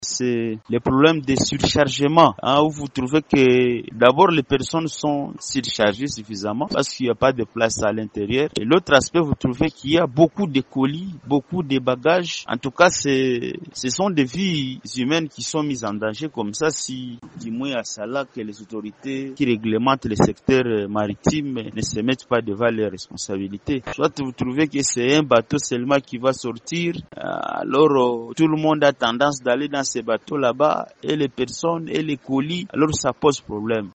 L’un des passagers qui fait des livraisons de consommables bureautiques à Bukavu et à Goma en parle.